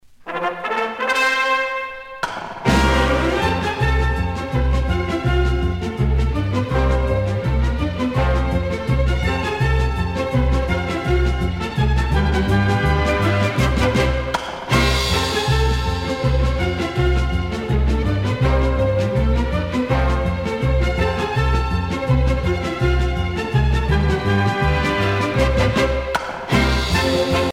danse : galop (danse)
Pièce musicale éditée